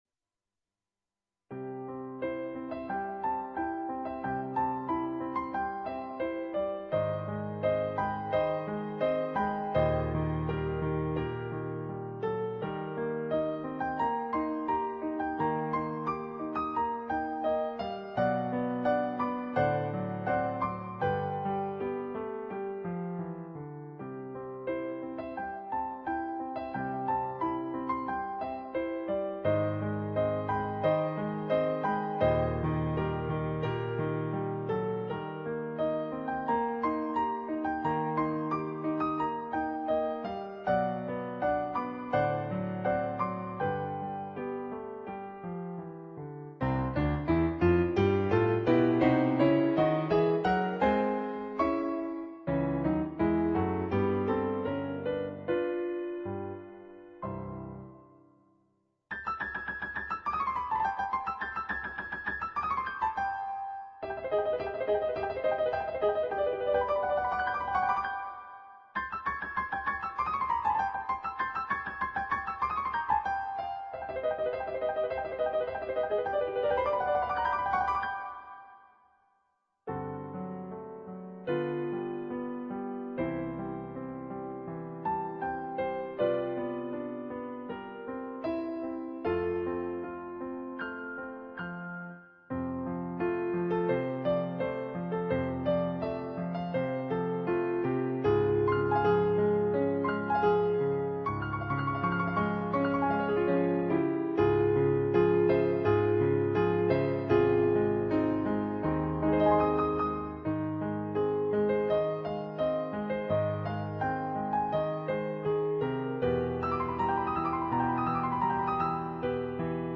Piano transcription